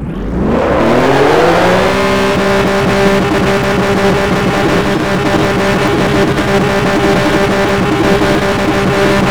Index of /server/sound/vehicles/lwcars/porsche_911_rsr
rev.wav